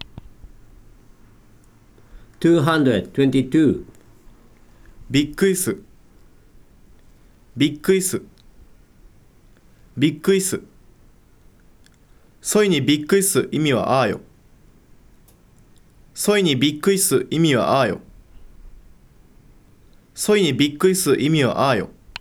If you click the word in a celll in the table, then you can hear the `non-past' form of the verb and a sentence containing the `non-past' form as the verb of the adnominal clause in Saga western dialect.
222. /bikkui suru/  `get surprised'